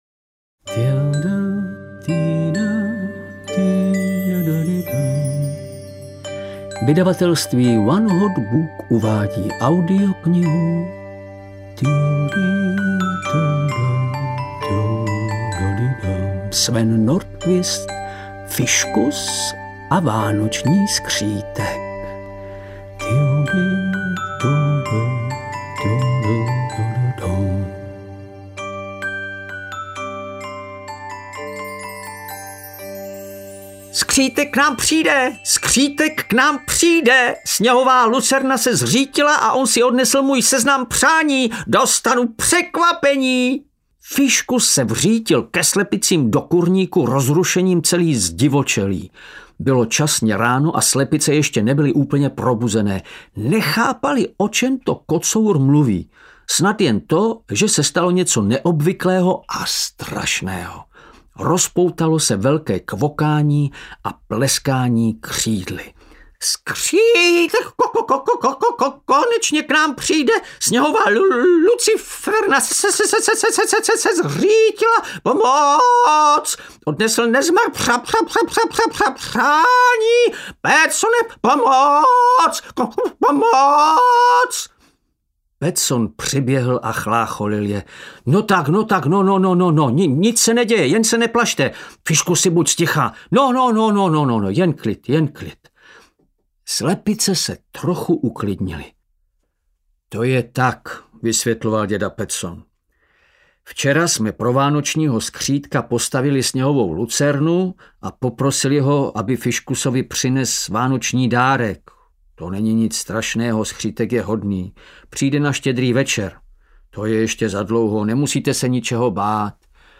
Fiškus a vánoční skřítek audiokniha
Ukázka z knihy
• InterpretVladimír Javorský
fiskus-a-vanocni-skritek-audiokniha